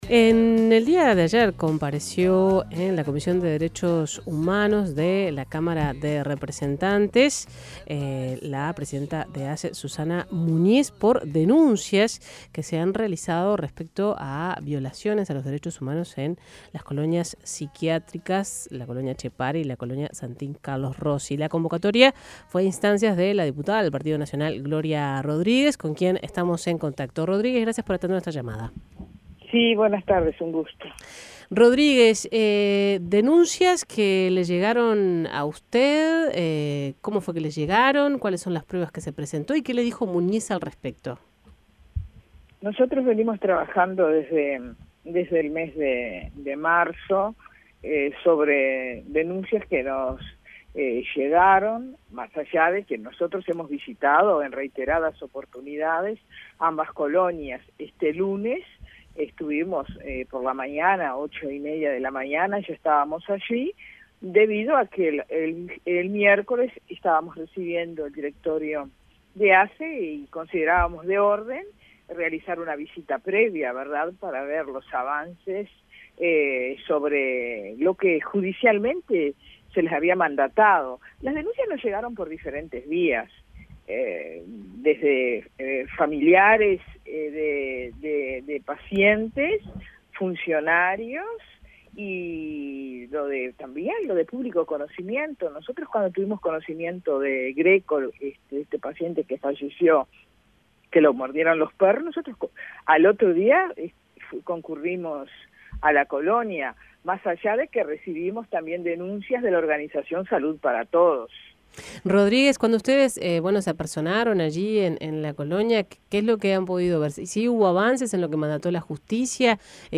Escuche la entrevista a Rodríguez